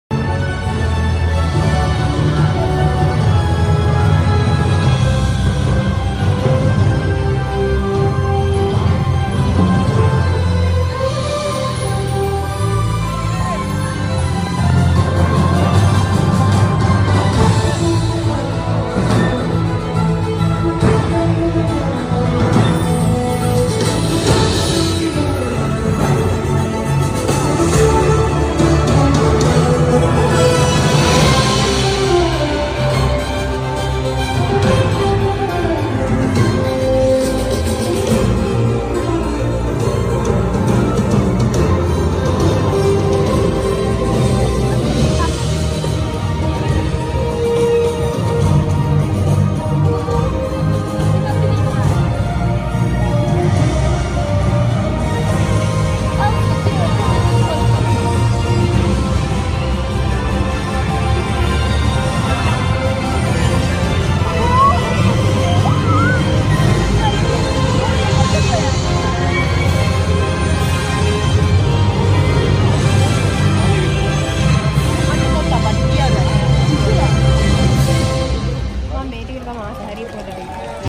universal studio japan Night Parade